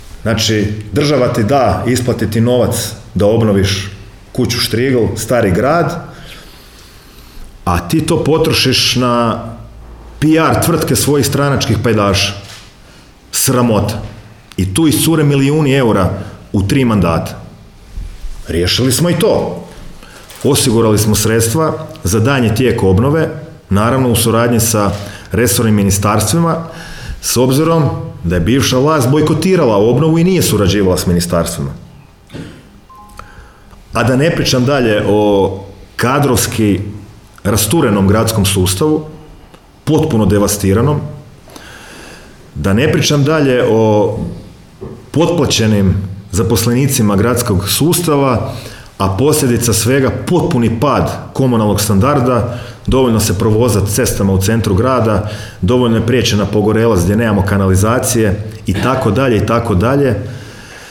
O presudi je, na tiskovnoj konferenciji održanoj u sisačkoj gradskoj upravi, govorio gradonačelnik Domagoj Orlić.